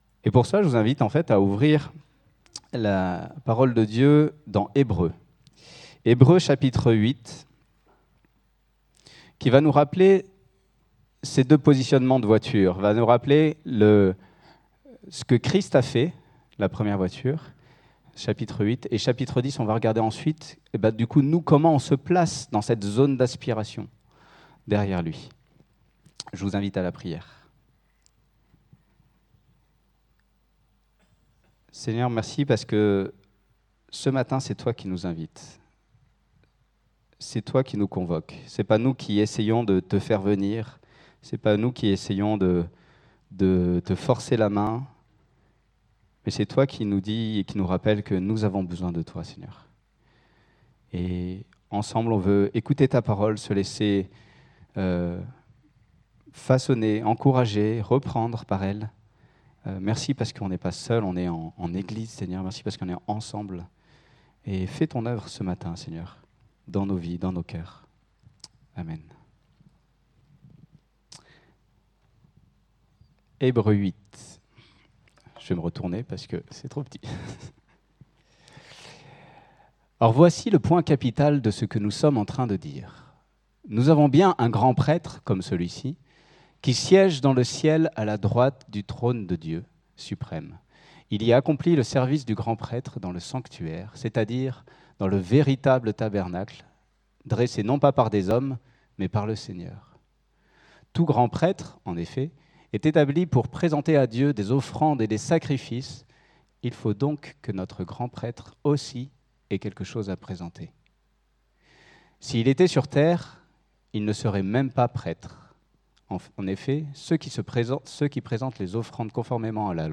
Un Dieu satisfait – Culte du 13/07/25